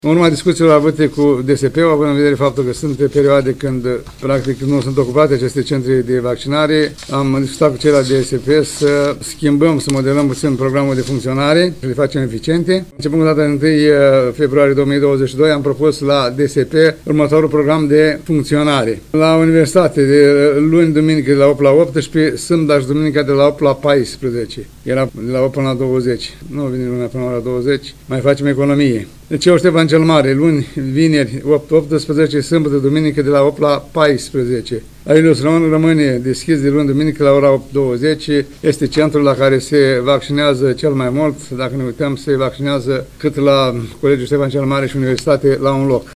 Primarul ION LUNGU a detaliat programul propus pentru aceste centre, începând de luna viitoare.